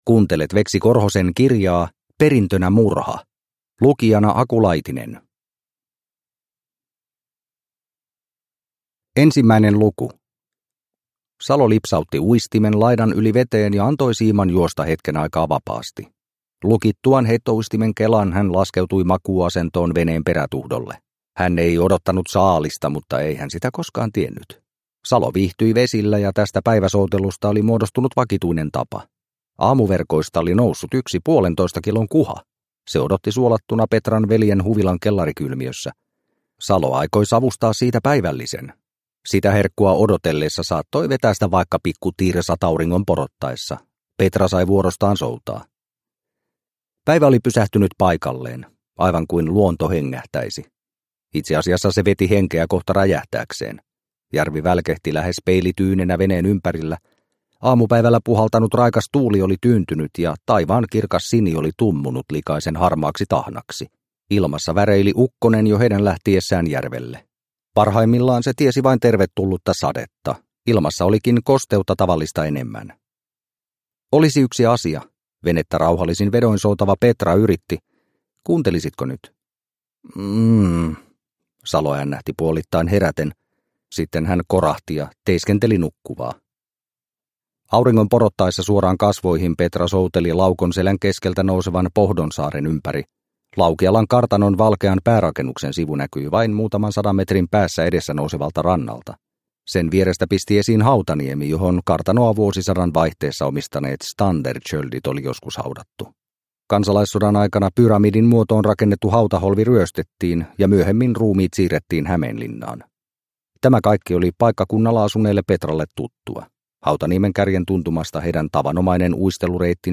Perintönä murha – Ljudbok – Laddas ner